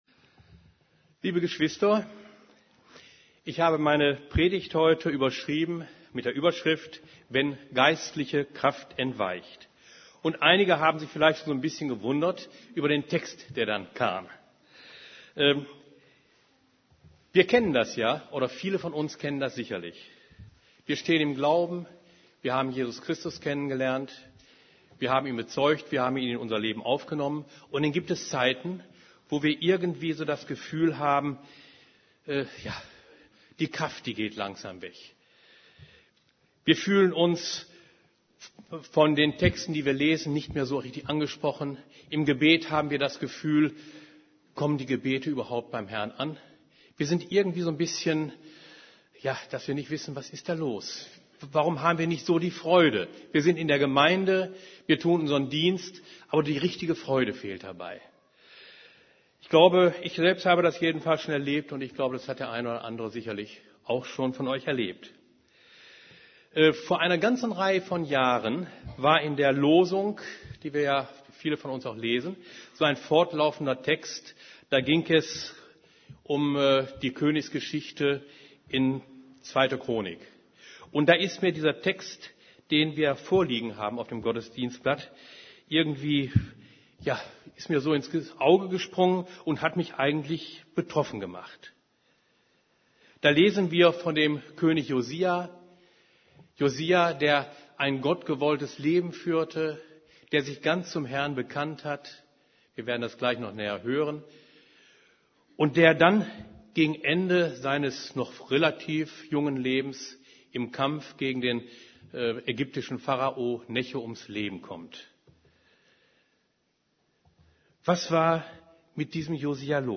> Übersicht Predigten Wenn geistliche Kraft entweicht Predigt vom 31.